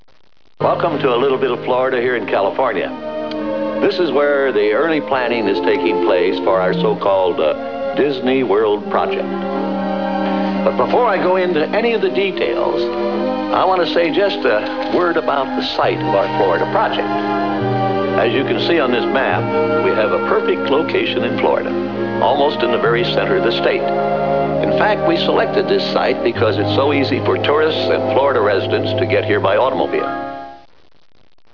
The following wav sounds are all recordings of Walt Disney speaking about Disney, Disneyland and Walt Disney World Florida.
Walt Disney speaks about the planning of